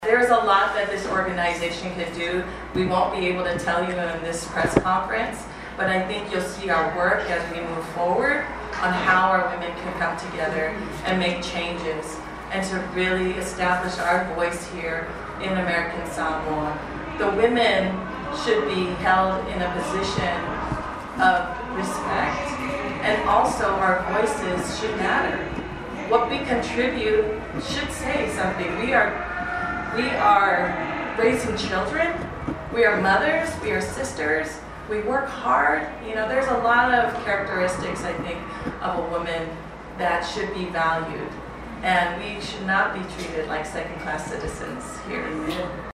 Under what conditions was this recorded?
The group held a press conference yesterday to introduce their leaders and explain the aims of their organization.